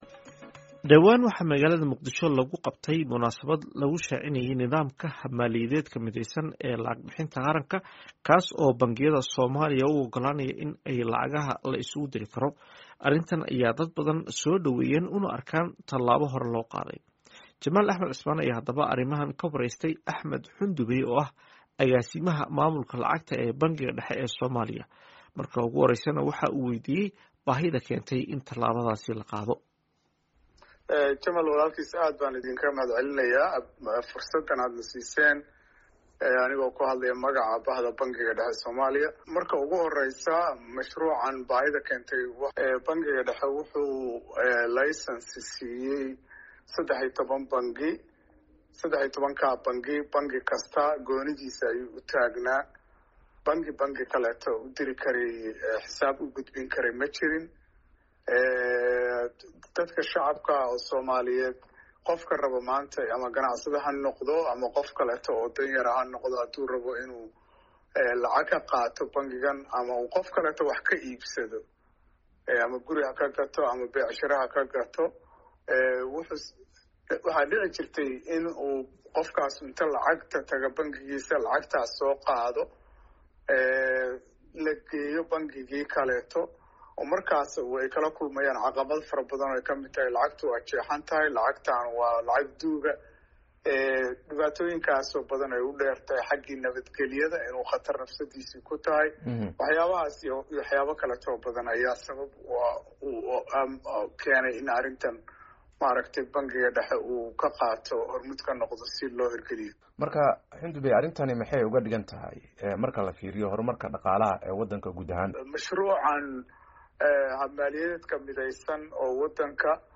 Wareysi: SIdee u shaqeeyaa hannaanka lacag-bixinta mideysan ee Qaranka?